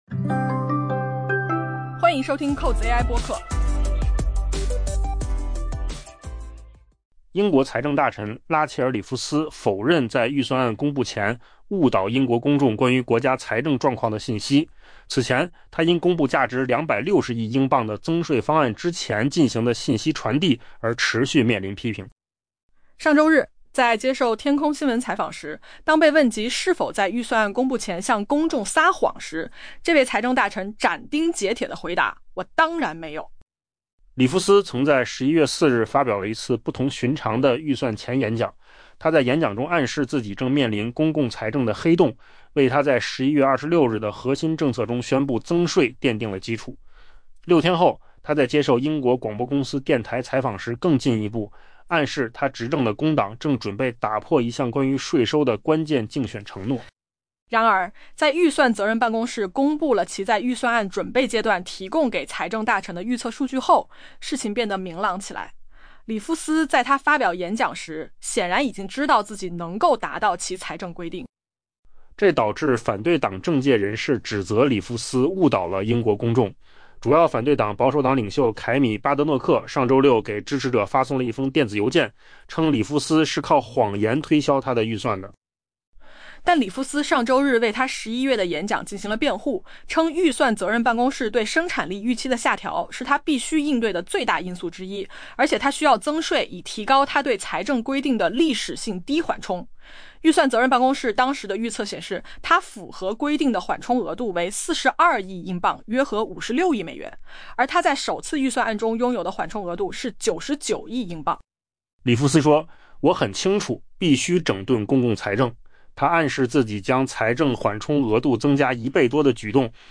AI播客：换个方式听新闻 下载mp3
音频由扣子空间生成